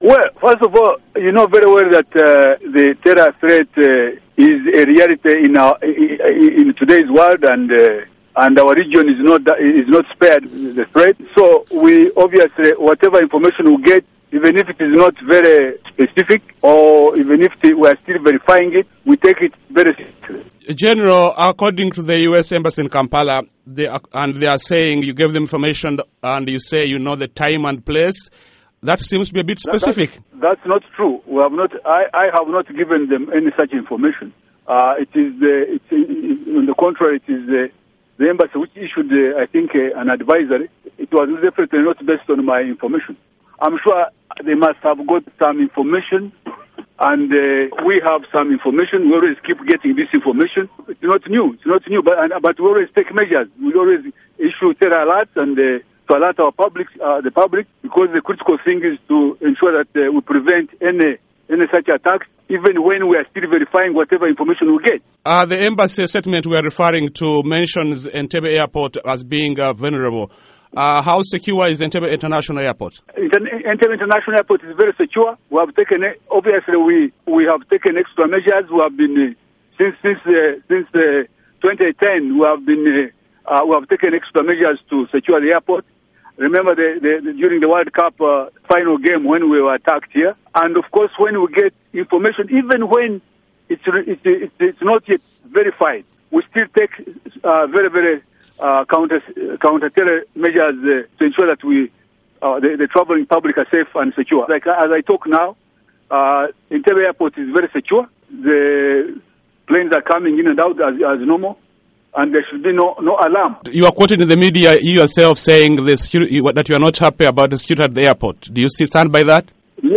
Listen to interview with Uganda’s Inspector General of Police General Kale Kayihura